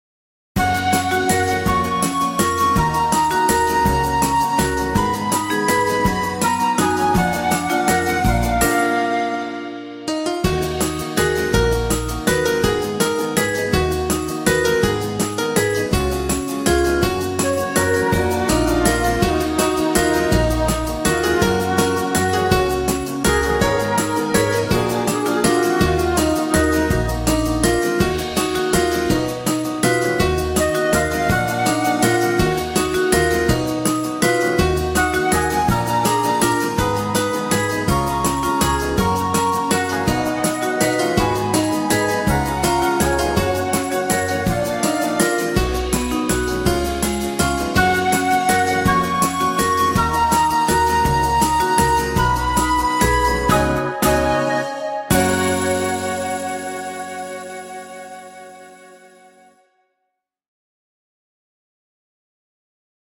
Колыбельная